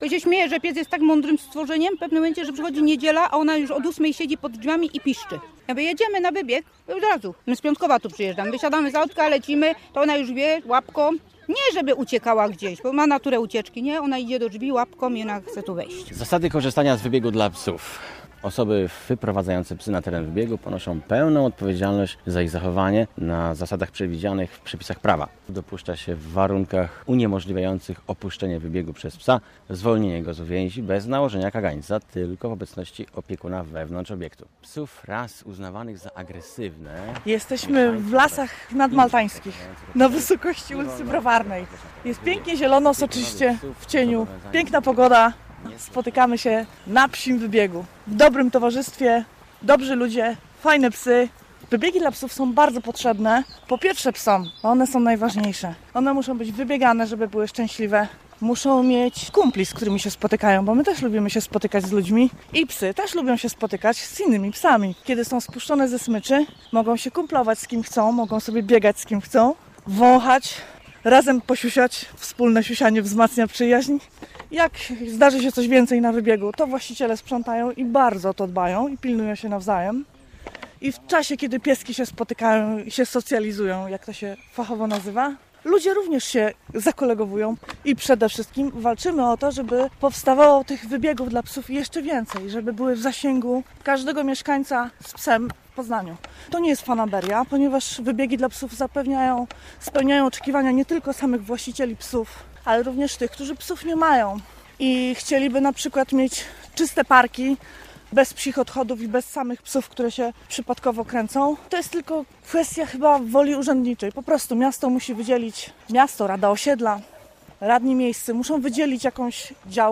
Drugi koniec smyczy - reportaż - Radio Poznań